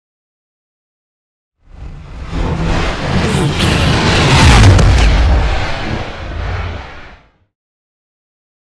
Scifi whoosh pass by shot
Sound Effects
futuristic
pass by
vehicle